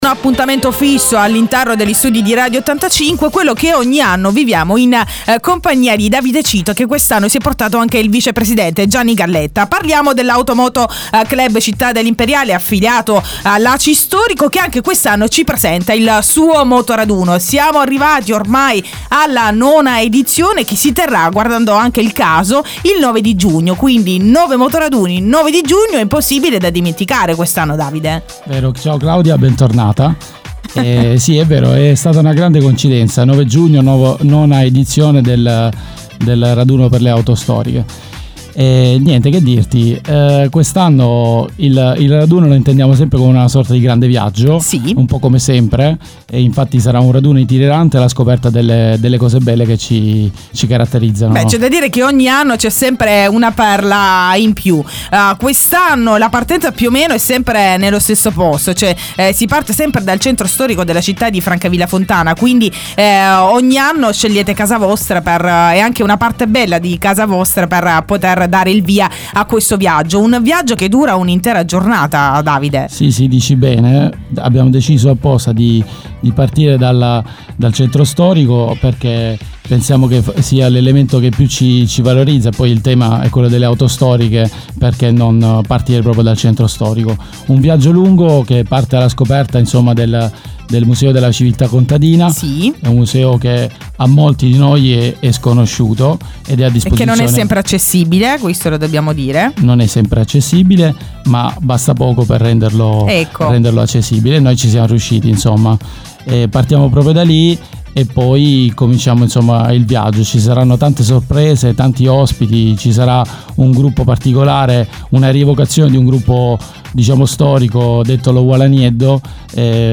Domenica 9 giugno nona edizione del raduno di moto d'epoca organizzato dall'Auto moto club Città degli Imperiali , affiliato ACI storico. In studio